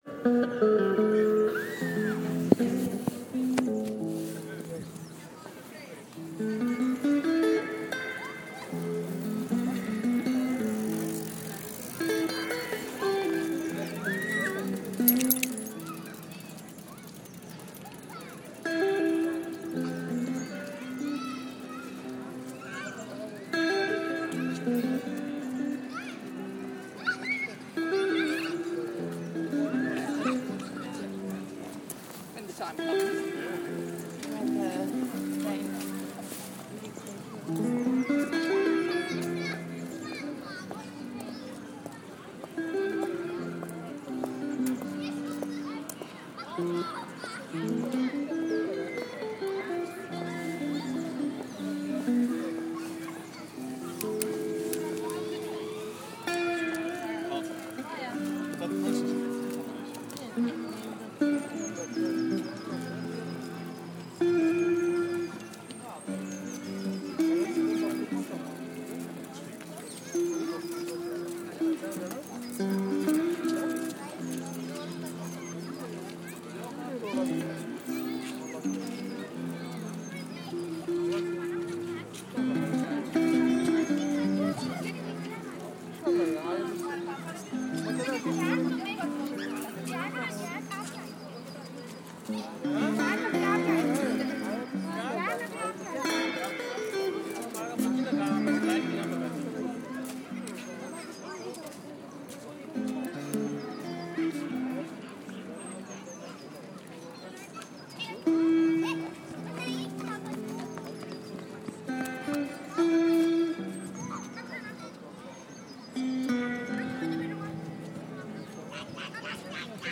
Knopfler-esque guitar busking on Richmond Riverside